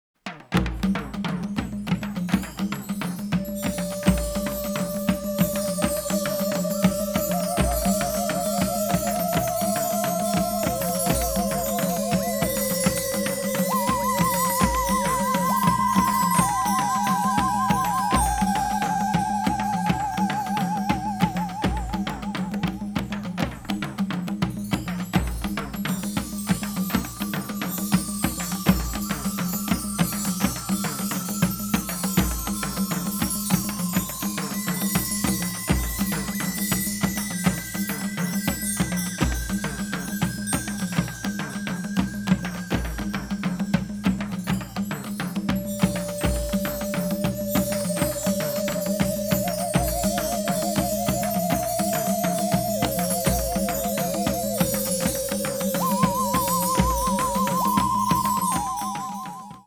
the usual eighties sound of synthesizers
a sort of new-age jazz mood
both are in pristine stereo sound.